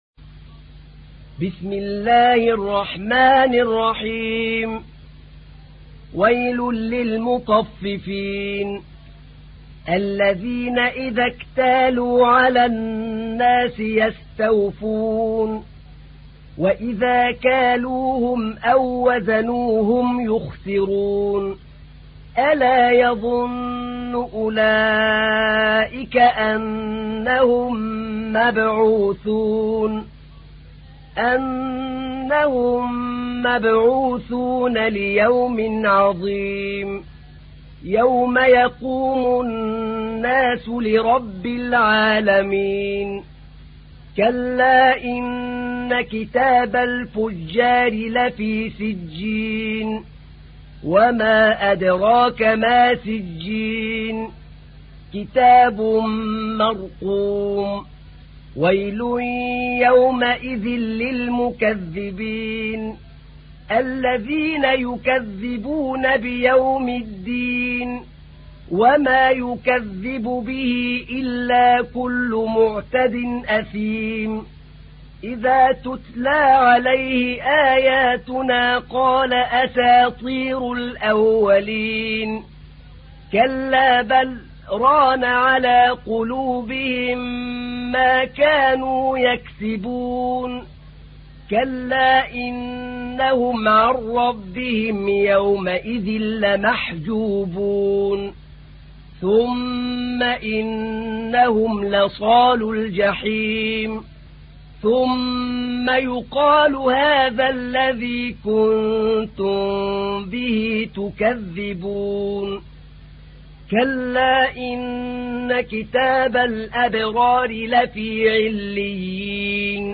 سورة المطففين / القارئ أحمد نعينع / القرآن الكريم / موقع يا حسين